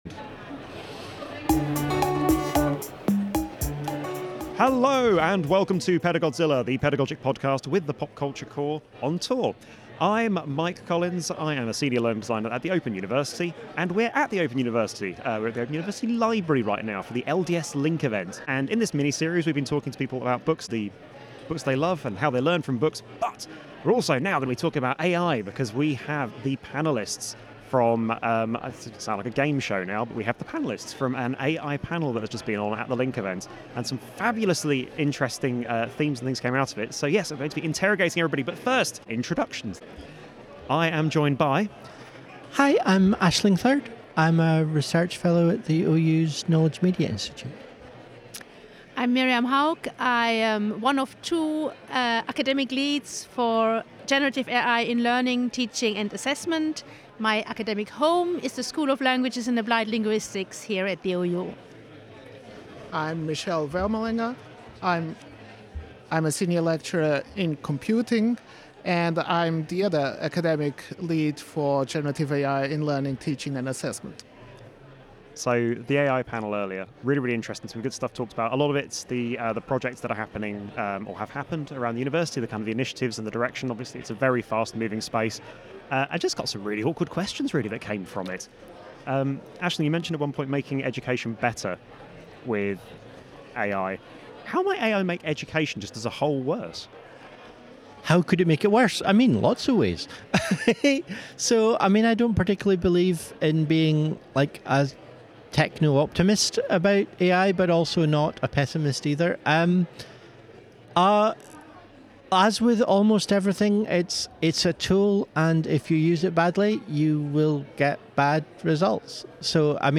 It’s another of our LDS Link event minis!